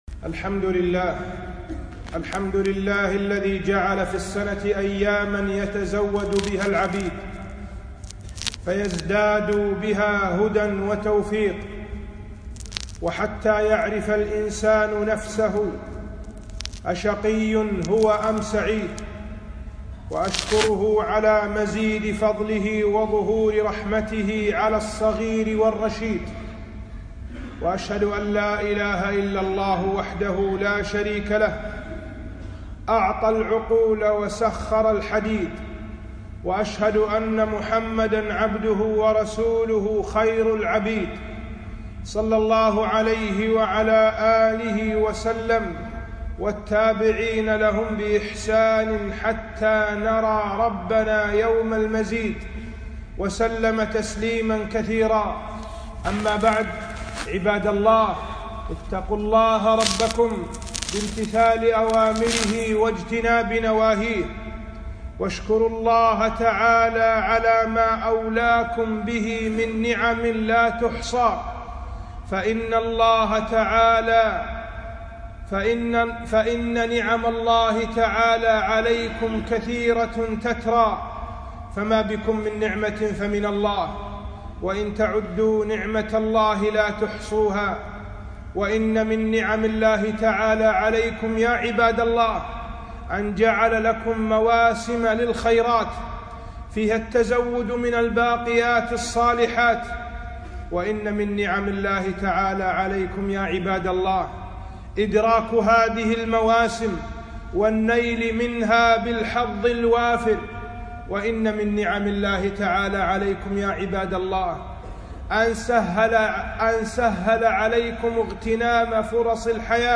خطبة - فضائل شهر رمضان 1439هــ